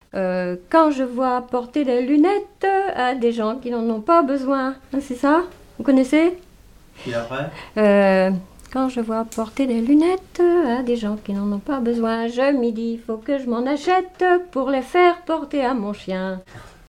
Chants brefs - A danser Résumé : Quand je vois porter des lunettes à des gens qui n'en ont pas besoin.
danse : mazurka
Pièce musicale inédite